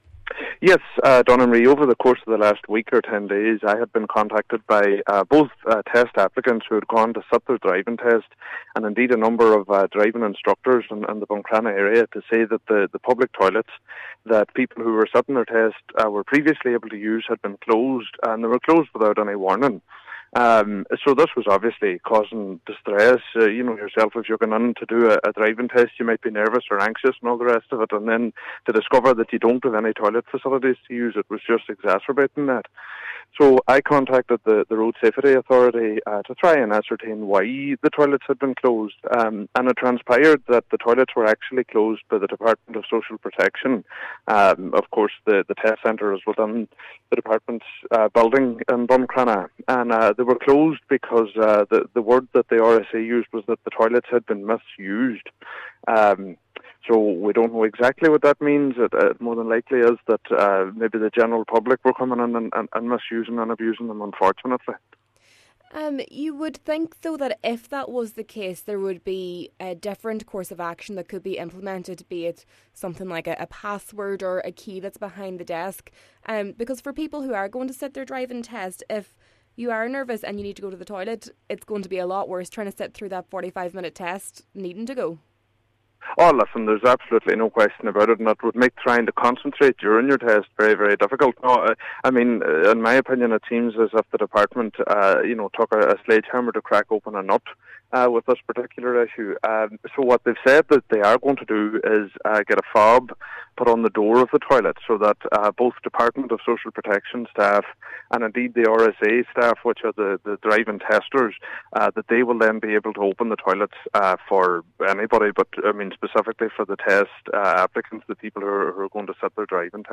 If this is the case, Cllr Bradley says the department has overreacted: